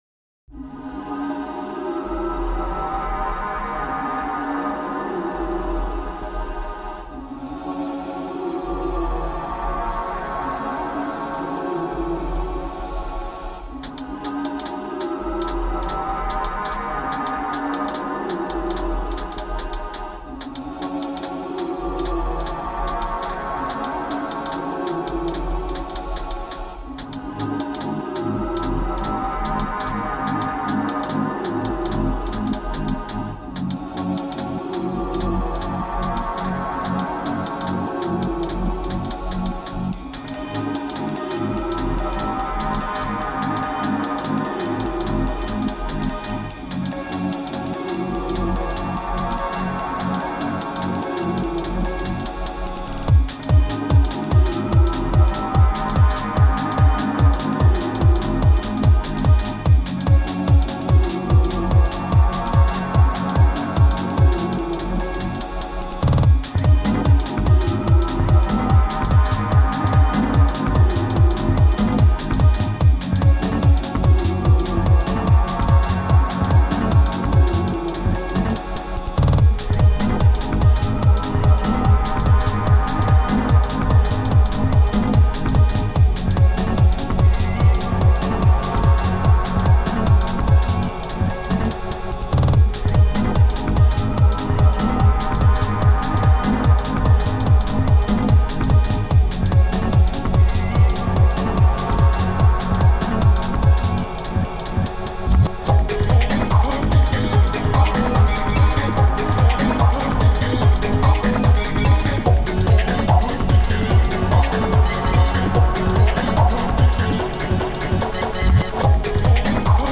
Pad Tech
ポイントはテクノなんだけれども、ベースをはじめリズムが跳ねていることと
クラッシュシンバルを一つも入れていないことが特徴で他にあまり類を見ない